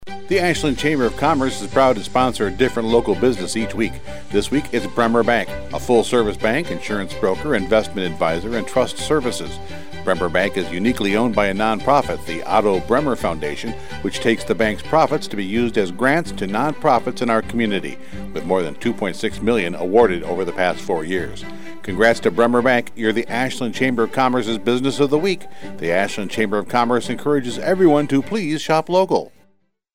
Each week the Ashland Area Chamber of Commerce highlights a business on Heartland Communications radio station WATW 1400AM and Bay Country 101.3FM. The Chamber draws a name at random from our membership and the radio station writes a 30-second ad exclusively for that business.